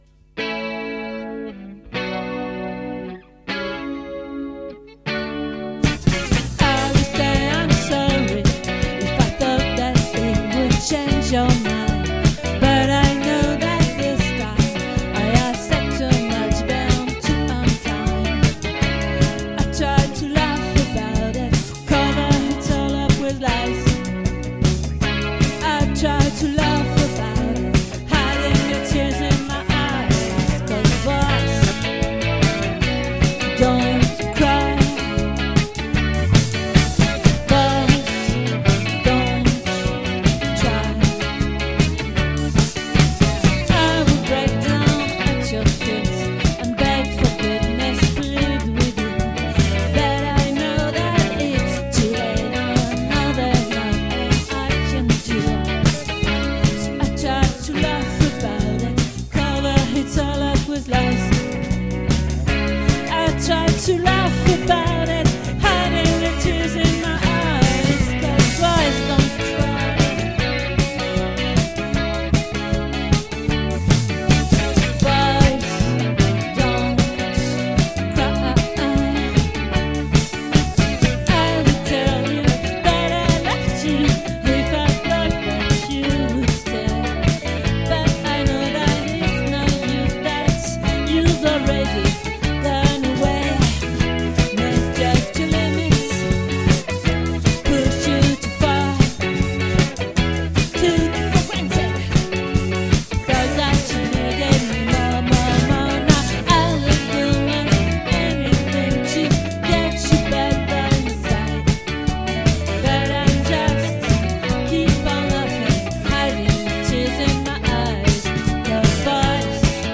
🏠 Accueil Repetitions Records_2022_11_16_OLVRE